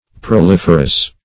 Proliferous \Pro*lif"er*ous\, a. [L. proles offspring +
proliferous.mp3